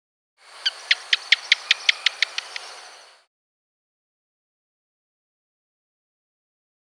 animal
House Gecko Call